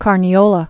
(kärnē-ōlə, kärn-yō-)